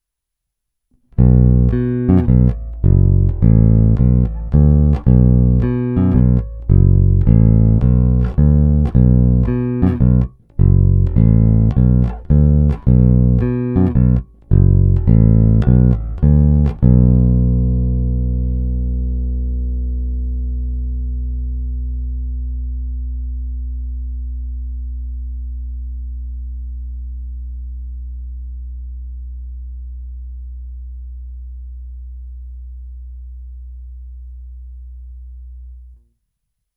Zvuk děleného P snímače poskytuje velice pěkný, typicky precižnovský zvuk, oproti tomu samotný kobylkový J snímač už moc z jazzbassu nepobral, je svůj, možná i zvolenou bezbrumovou konstrukcí, za kterou jsem na druhou stranu rád.
Tónovou clonu jsem nechal otevřenou.
Ukázky jsou nahrány rovnou do zvukové karty a jen normalizovány.
Snímač u krku